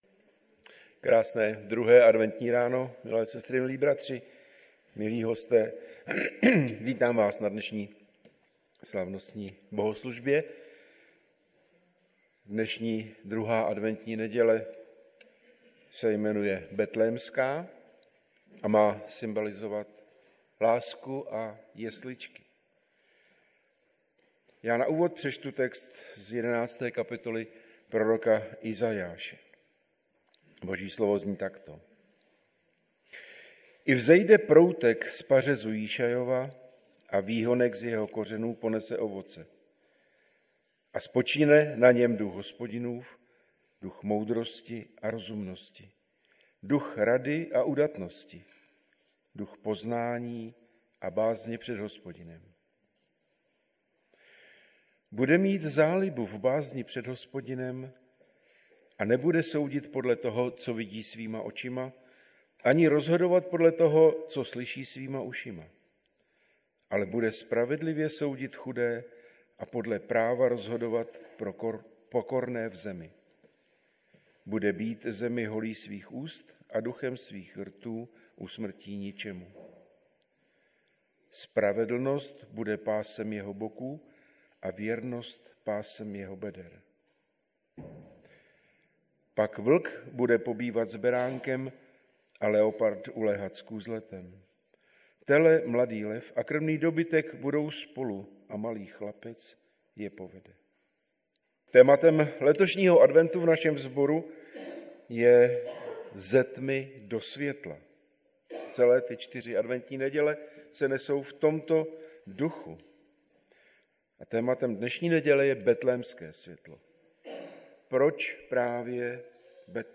Nedělní bohoslužby Husinec přehrát